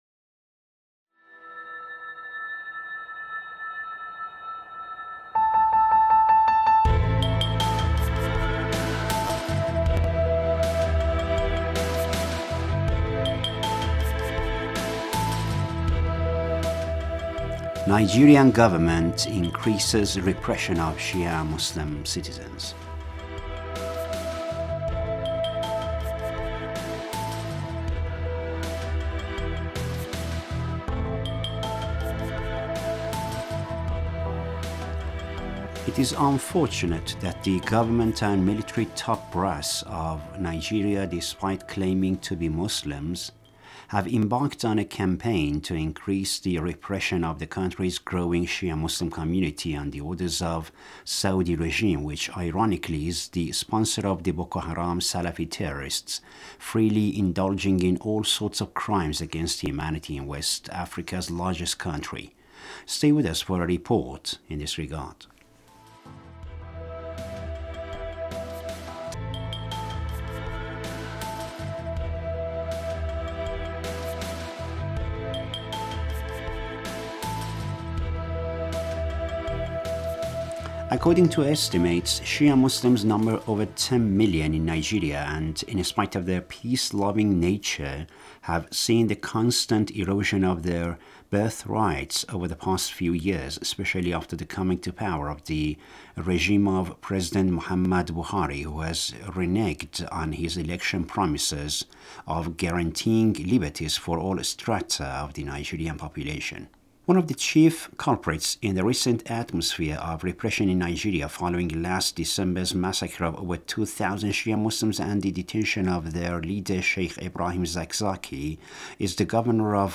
Now we have a report in this regard.